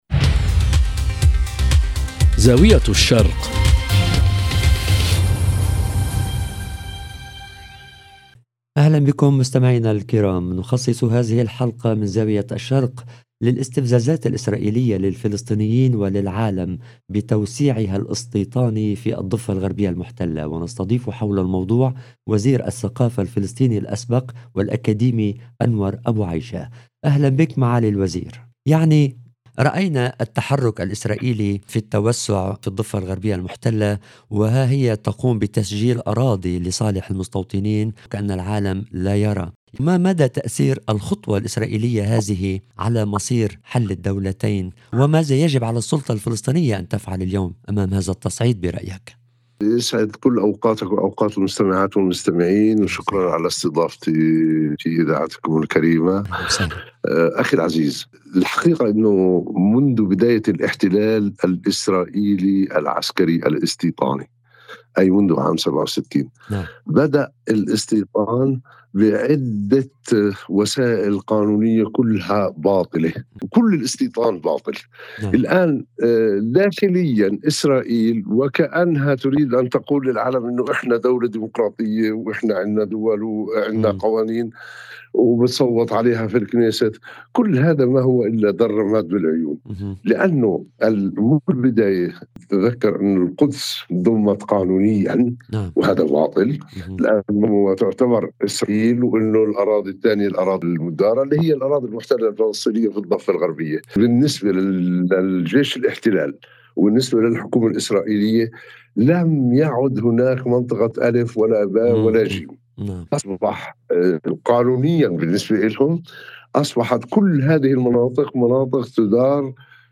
ضيف الحلقة وزير الثقافة الفلسطيني الأسبق أنور أبوعيشة الذي هاجم بشدة الخطوات الاسرائيلية.